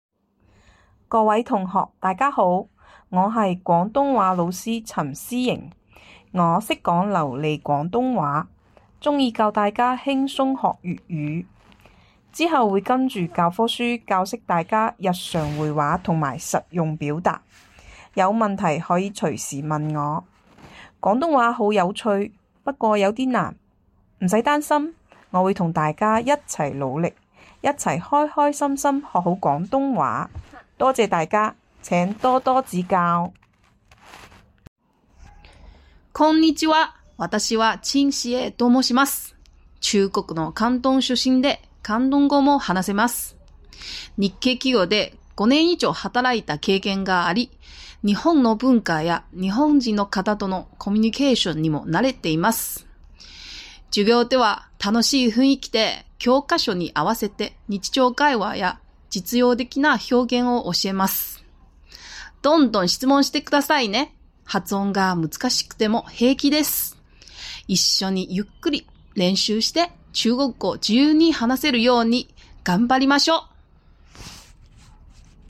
音声の自己紹介